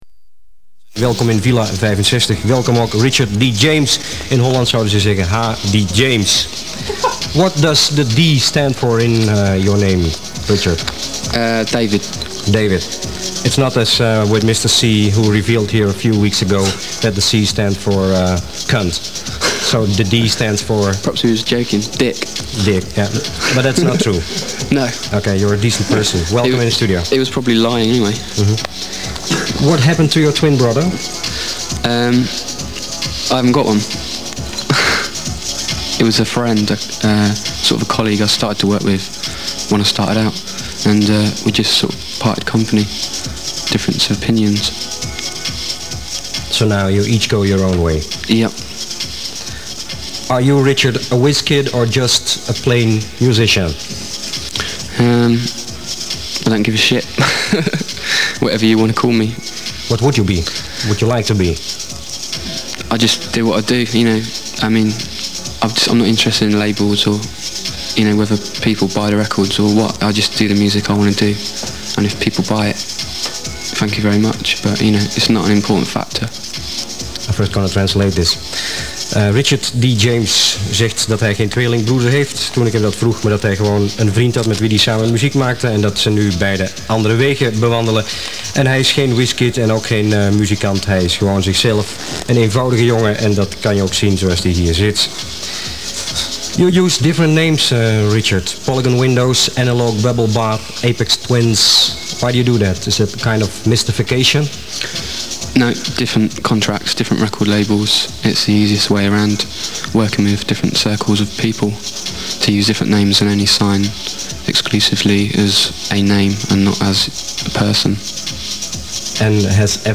location Netherlands, Hilversum